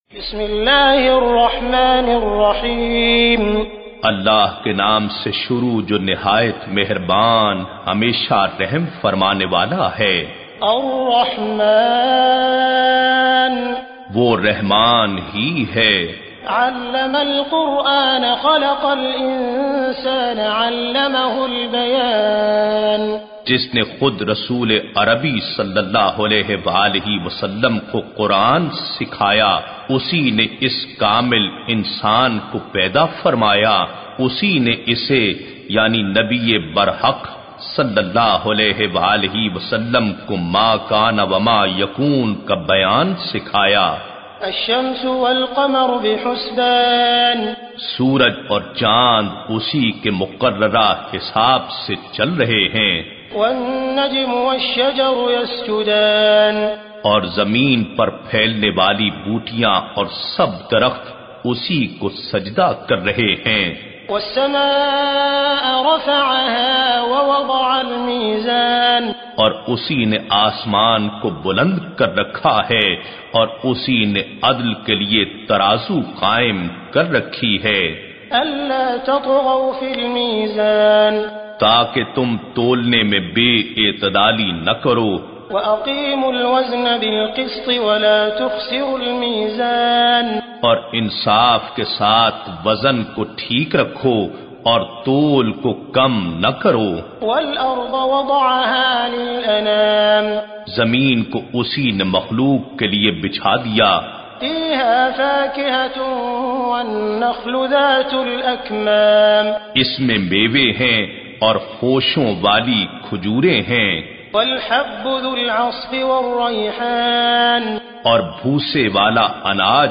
ar-Rahman(the-Most-Merciful)-with-urdu-translations.mp3